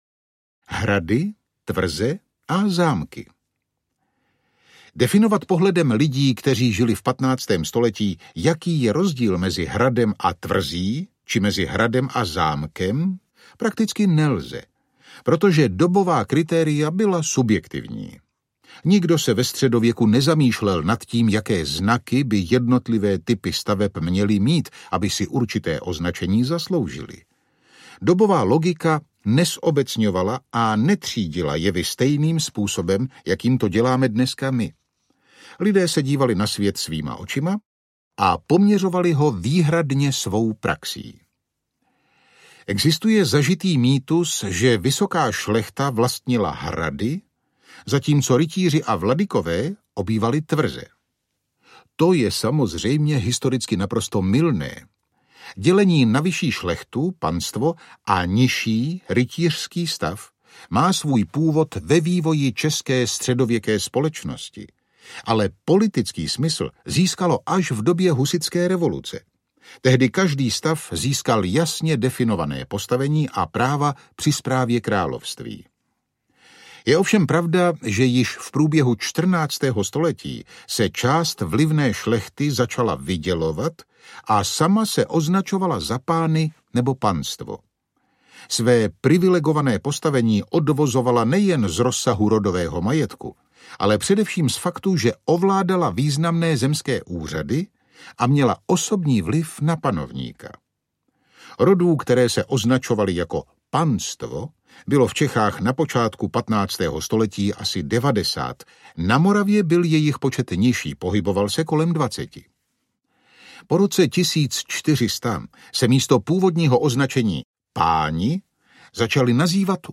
Život ve staletích – 15. století audiokniha
Ukázka z knihy
Vyrobilo studio Soundguru.